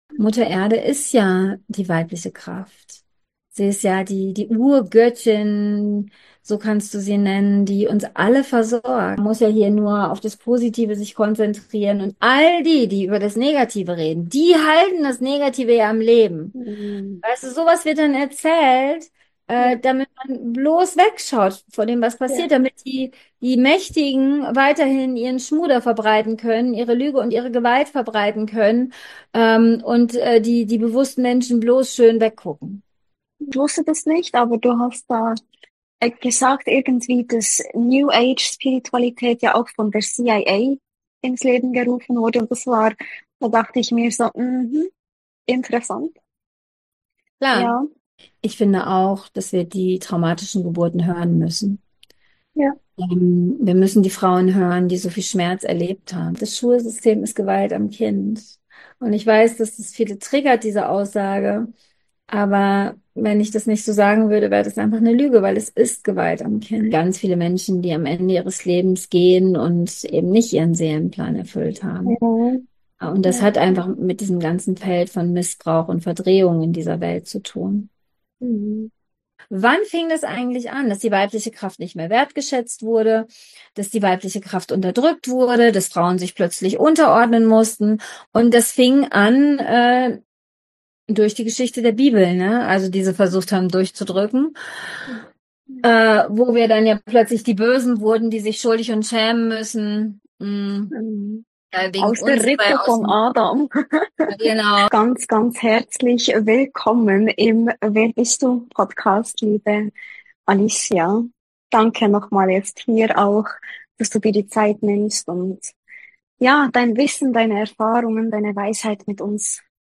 Interview ~ WER BIST DU? Podcast